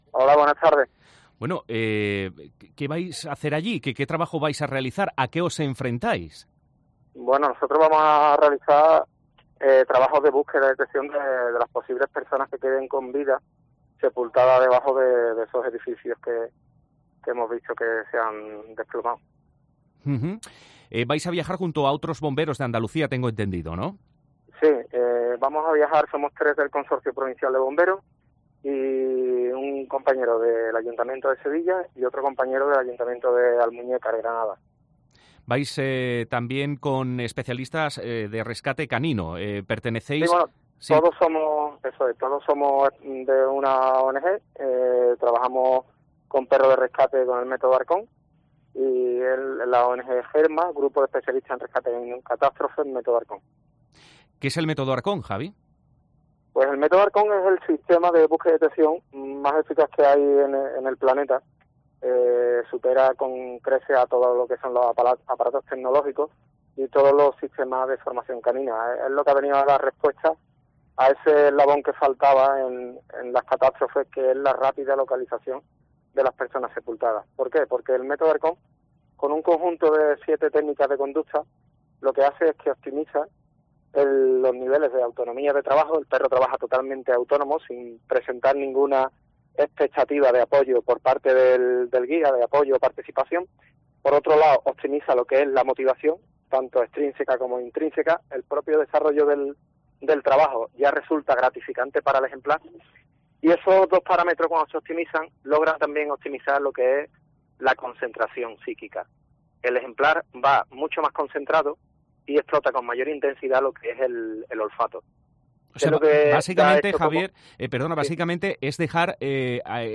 Málaga provincia